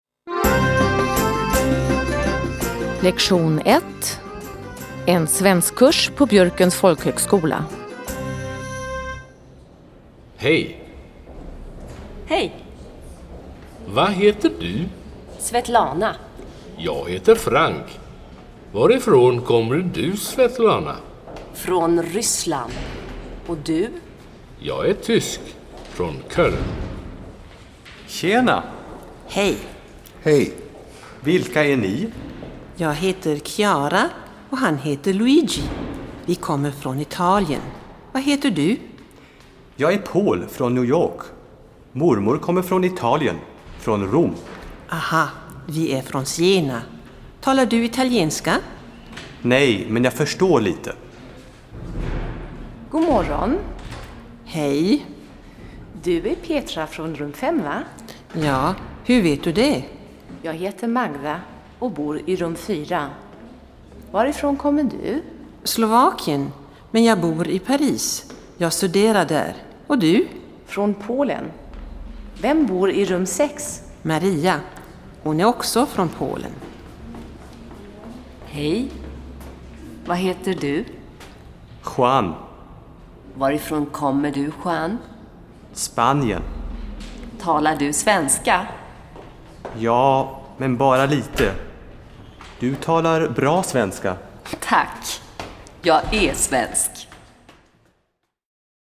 3 Audio-CDs mit Dialogen in zwei Sprechgeschwindigkeiten und abwechslungsreichem Hör- und Sprechtraining